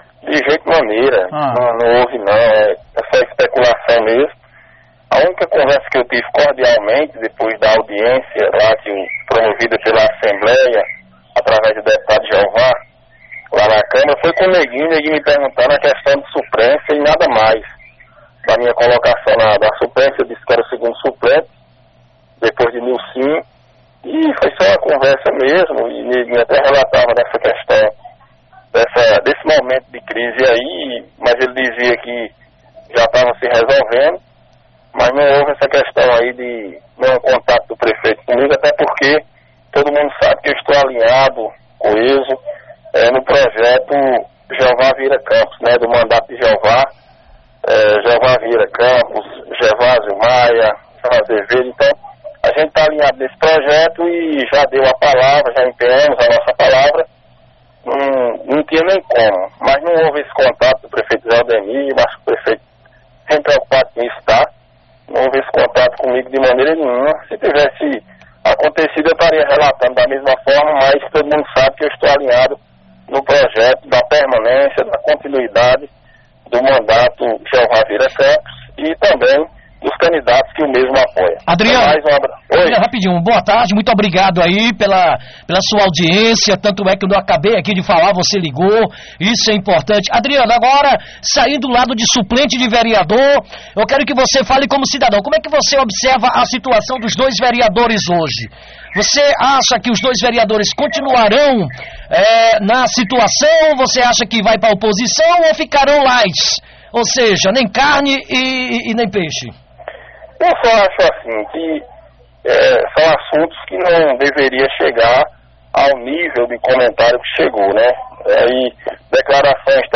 A confirmação foi feita ao programa Rádio Vivo pelo suplente de vereador, ”Nunca existiu isso” apenas conversei com o vereador Neguim d0o Mondrean, mais o assunto foi outro.